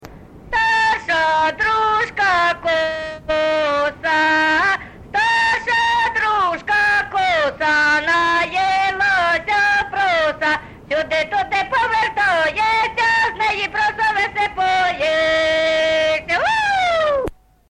ЖанрВесільні
Місце записус. Харківці, Миргородський (Лохвицький) район, Полтавська обл., Україна, Полтавщина